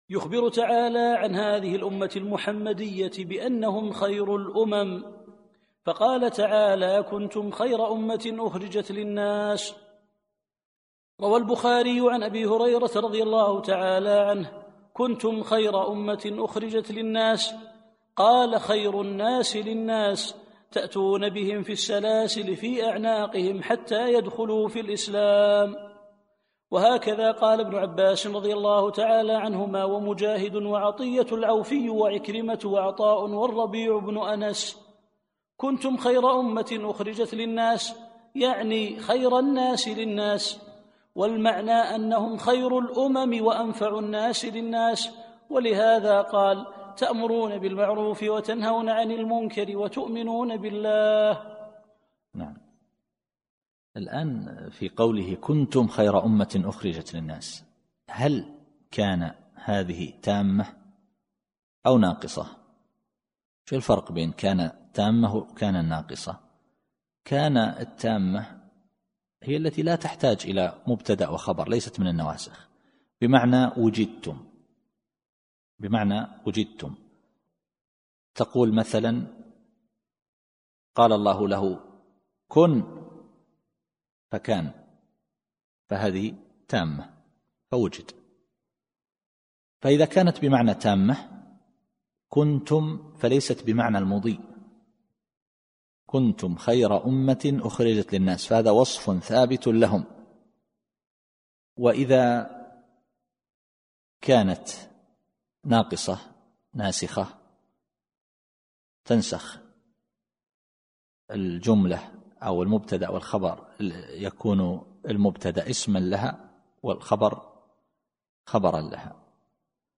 التفسير الصوتي [آل عمران / 110]